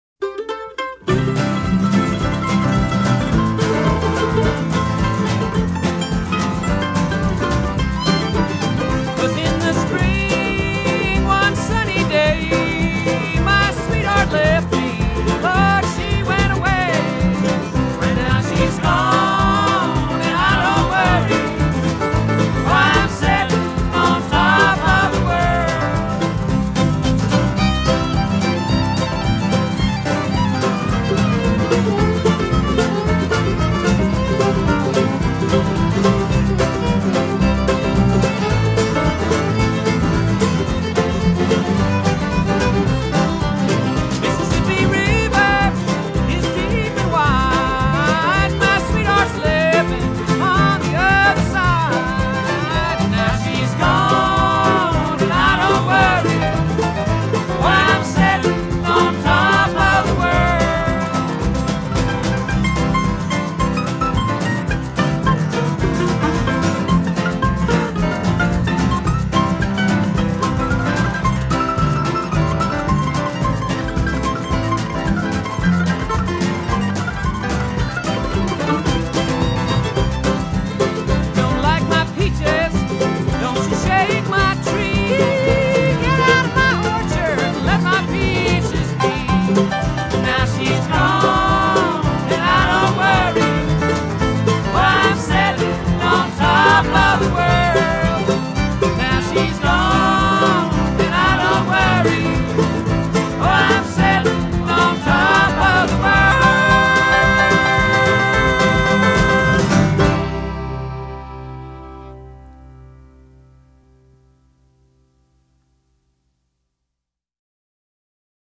doing the old folk-blues chestnut.